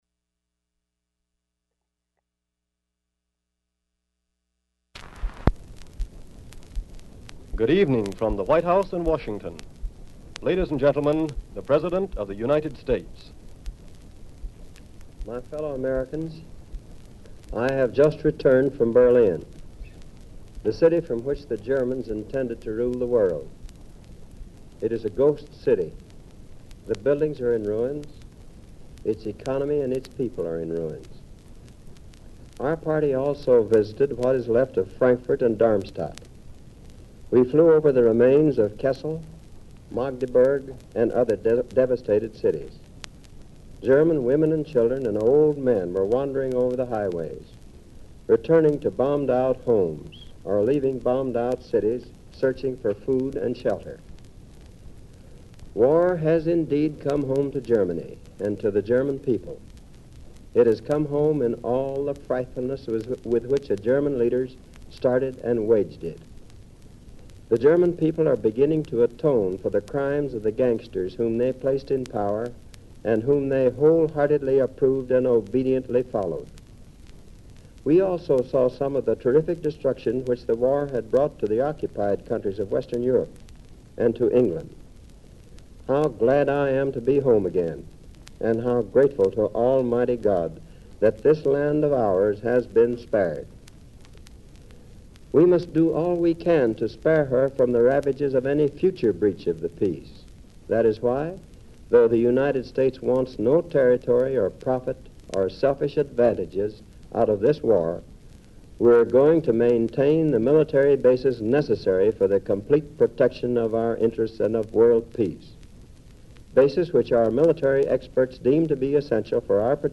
Address by President Truman on the Potsdam Conference August 9, 1945, 10 p.m. EWT
Delivered from the White House